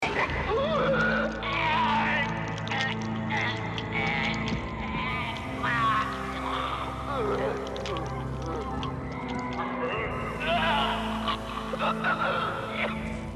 Good on Max for dropping the mic near enough for everyone in the convention hall to hear it.
videodrome-convex-dying.mp3